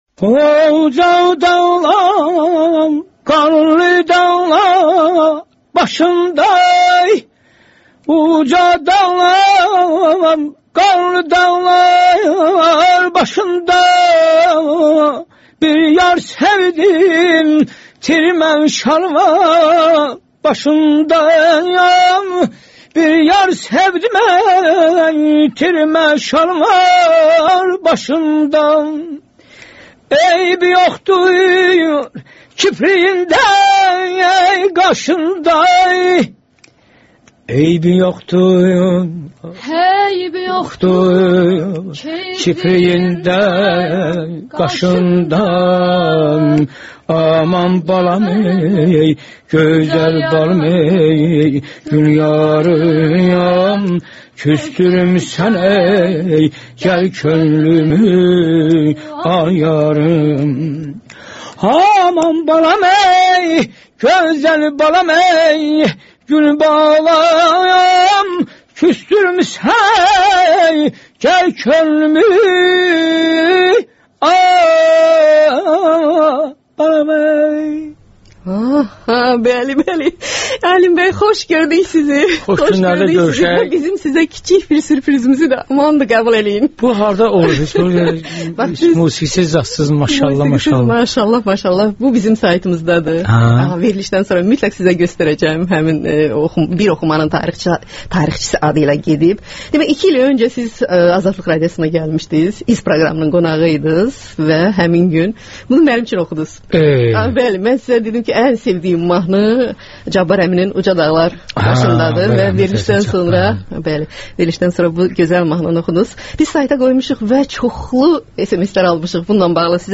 Alim Qasımovla müsahibə - 1-ci hissə